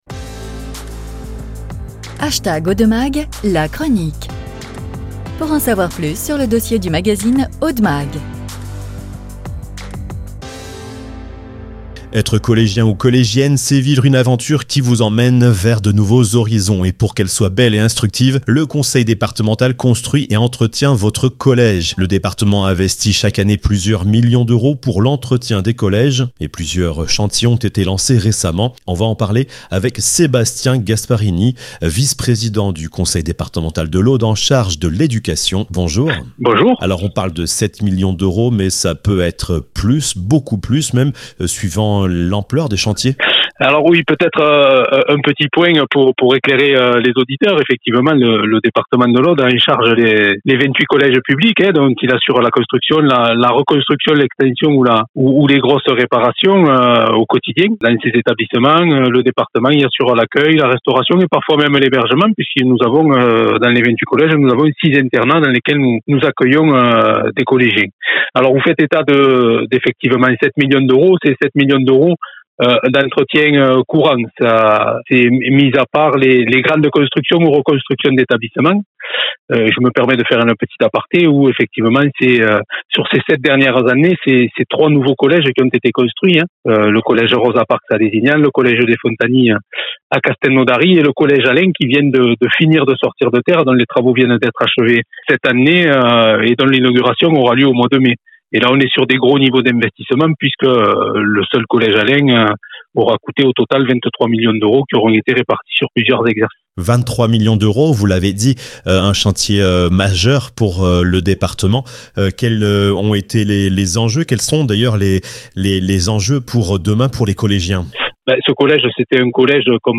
Interview - Investissements, chantiers, enjeux pour les élèves : comment le département de l'Aude accompagne les jeunes ?